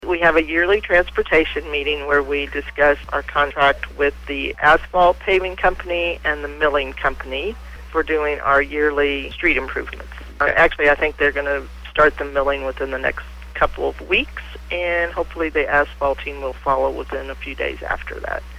City Administrator Jeanette Dobson talks about the bills.